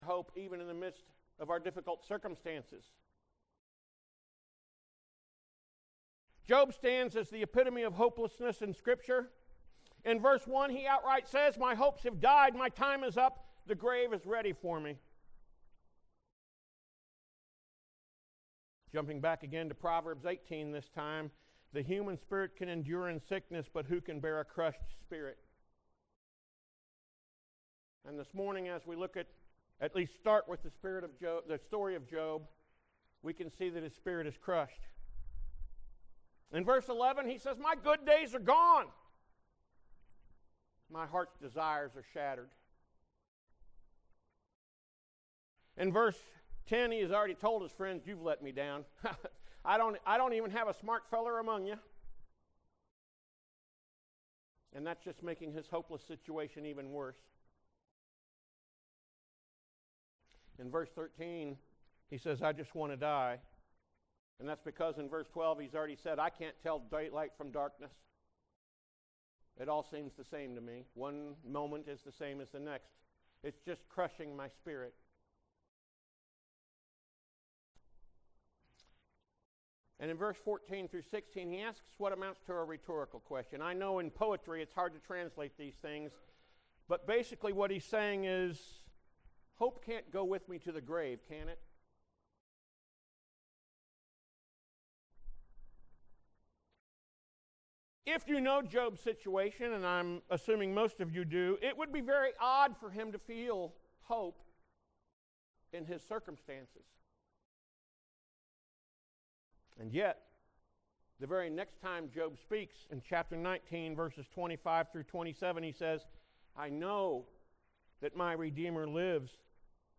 Sermons | Central Church of Christ